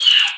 PixelPerfectionCE/assets/minecraft/sounds/mob/bat/hurt2.ogg at ca8d4aeecf25d6a4cc299228cb4a1ef6ff41196e